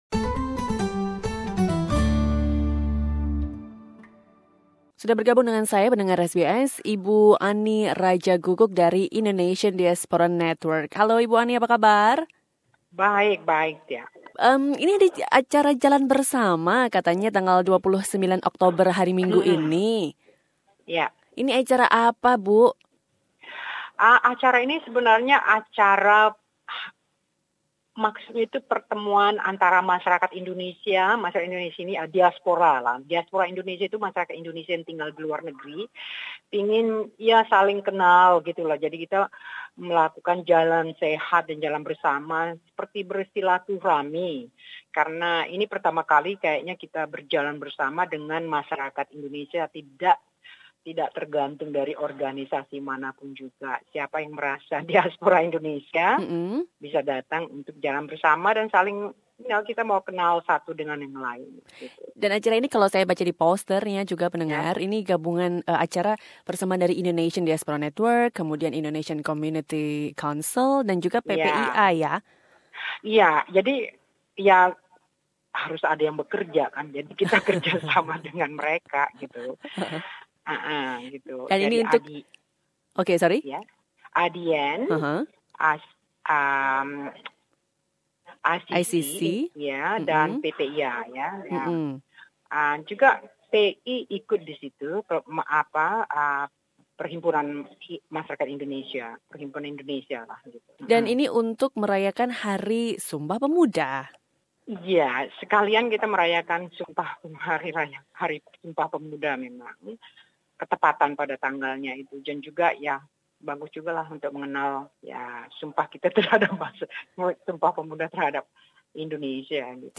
Dalam rangka Sumpah Pemuda, Indonesian Diaspora Network bersama dengan Indonesian Community Council dan PPIA NSW menyelenggarakan acara "Jalan Bersama 1017". SBS Radio berbincang